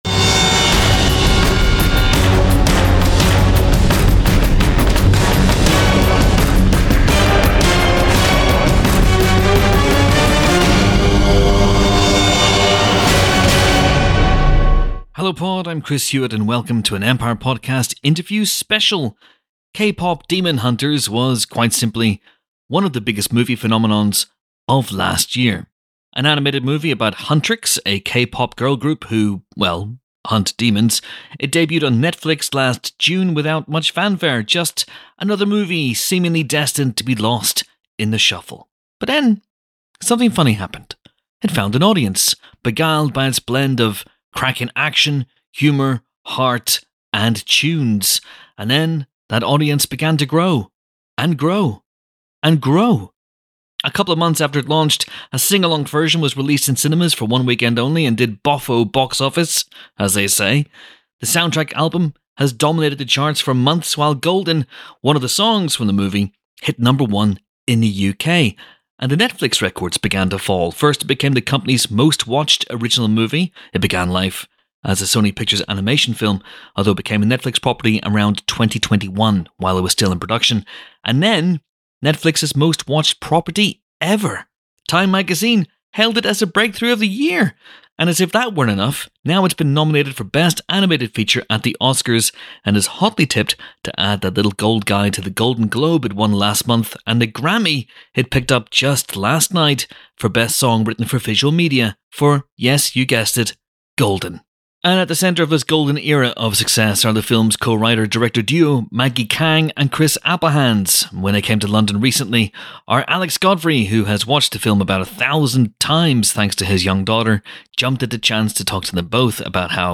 KPop Demon Hunters: An Empire Podcast Interview Special